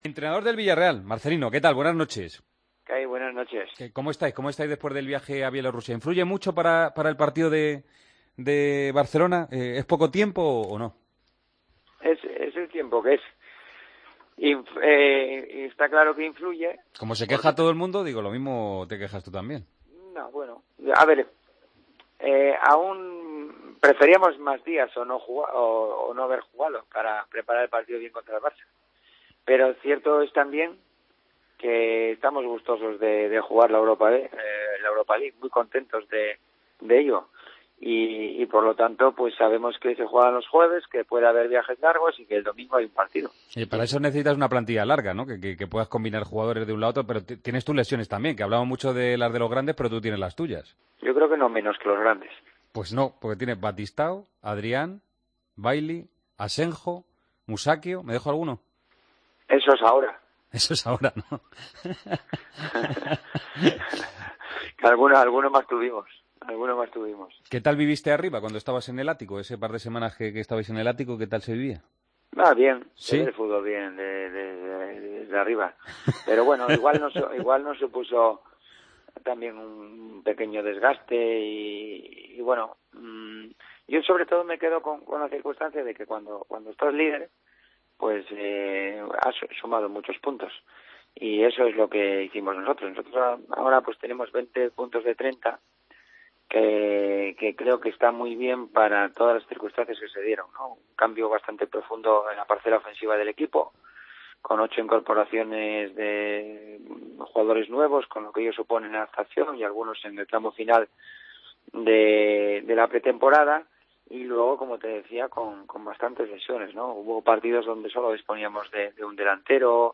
El técnico del Villarreal habló en Tiempo de Juego en la previa del encuentro contra el Barcelona: "Si tuviéramos todo el potencial arriba tendríamos mucha pegada. En la faceta ofensiva no estamos al nivel del año pasado, somos un equipo joven y a veces nos falta esa competitividad y nos dejamos puntos. Ambos equipos tenemos bajas. Vamos a ir con mucha ilusión y en 90 minutos ¿por qué no vamos a sorprender al Barcelona?. No tengo ninguna prisa sobre la renovación, y tengo la impresión de que llegará pronto el acuerdo".
Con Paco González, Manolo Lama y Juanma Castaño